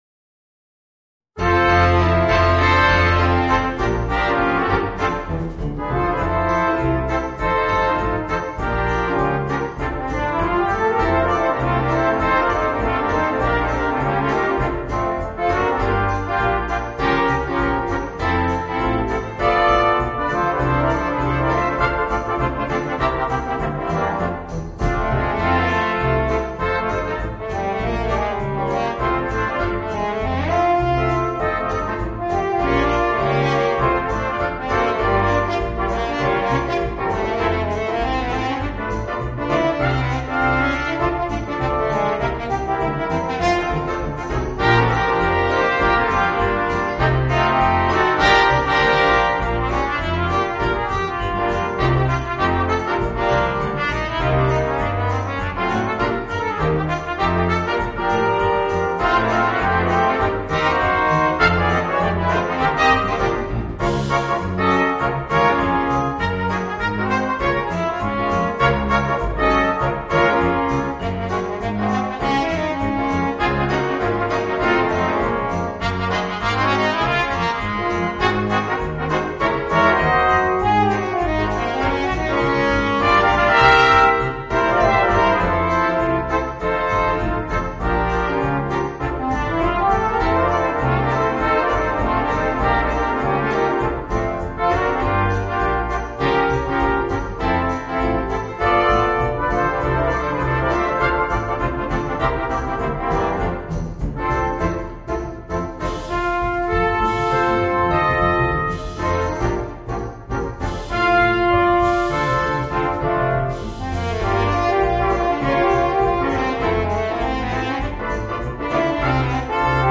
Voicing: 2 Trumpets, Trombone, Euphonium and Tuba